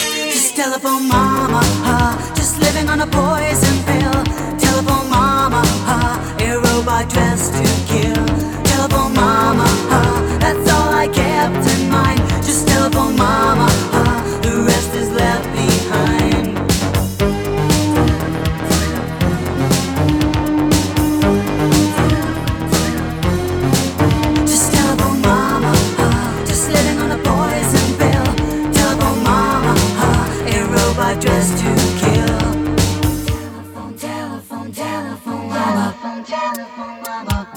поп
disco